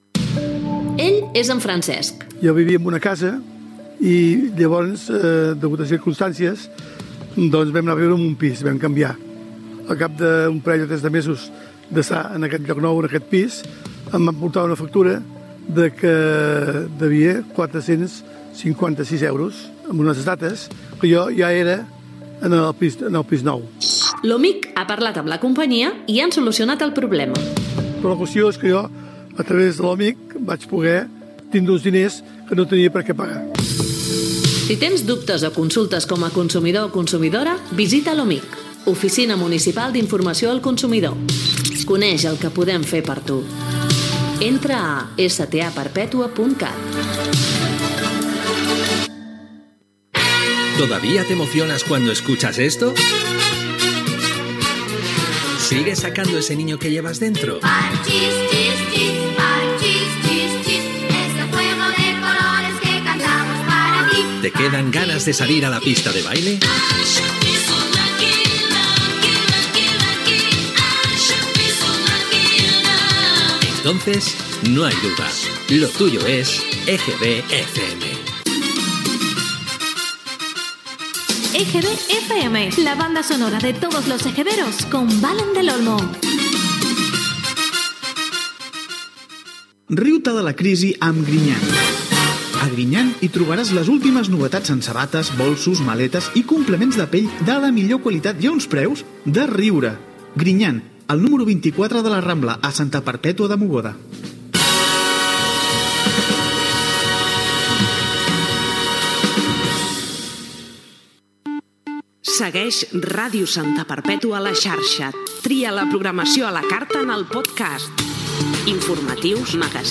Publicitat, promoció "EGB FM", publicitat, Ràdio Santa Perpètua a la xarxa Internet, indicatiu del programa, tema musical
FM